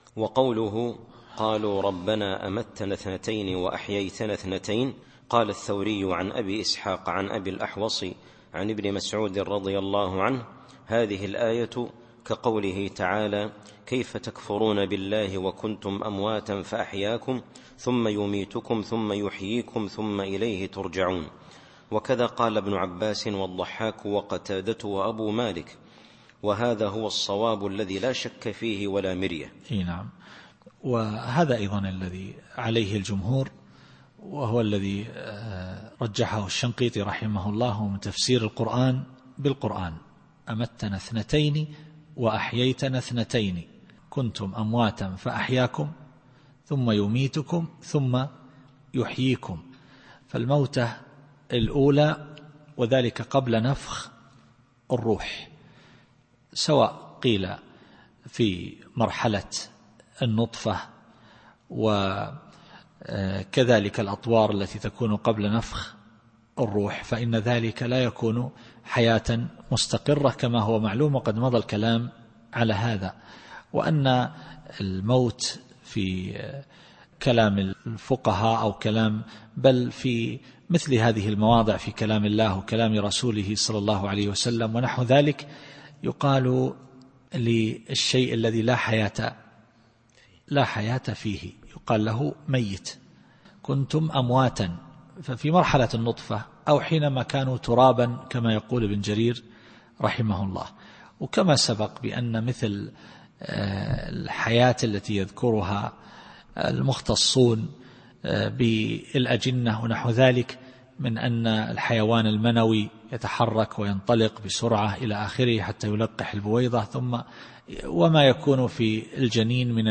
التفسير الصوتي [غافر / 12]